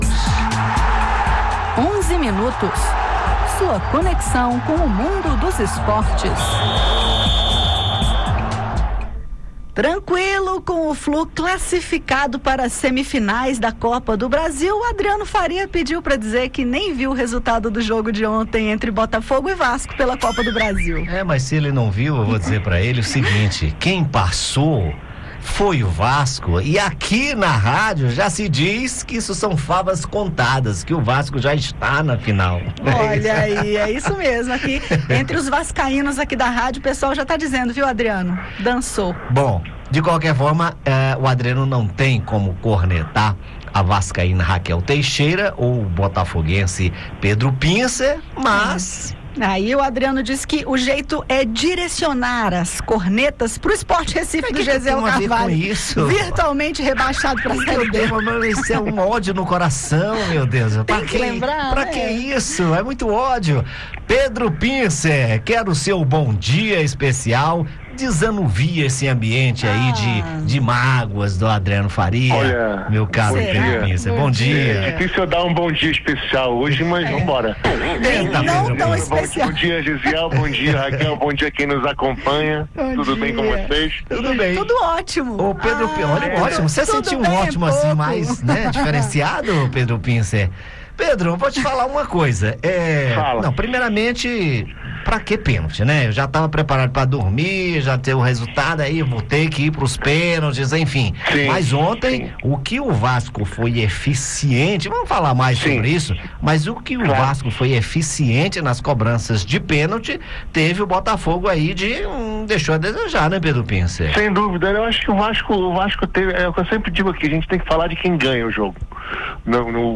Ouça os detalhes sobre o caso e o pronunciamento da senadora Leila Barros. Confira, na sequência, os comentários sobre a definição dos semifinalistas da Copa do Brasil, a rodada do Brasileirão e Bia Haddad brilhando no tênis e avançando às quartas de final do SP Open.